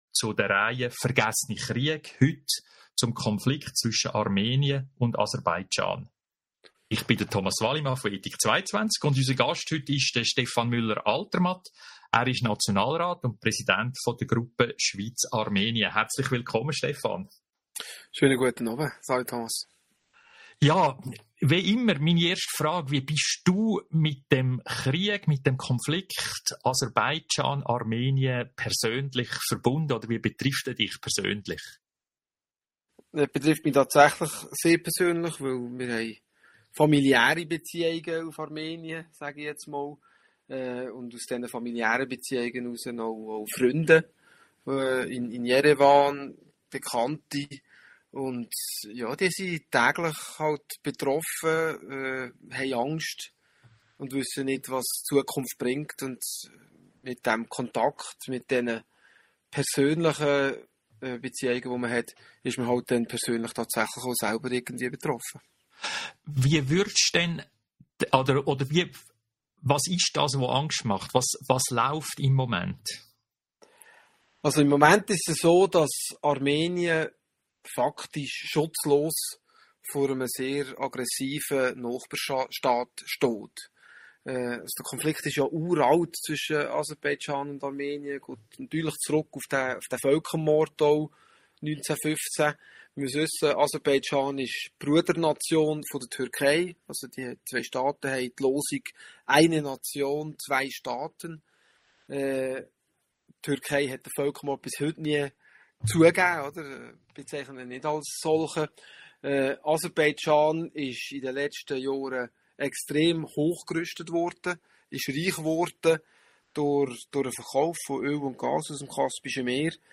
Stefan Müller-Altermatt , Gast bei Radio🎙einFluss Weitere interessante Gedanken zum Thema hören Sie im Podcast unseres Gesprächs vom 27. März 2024 mit Stefan Müller-Altermatt , Nationalrat.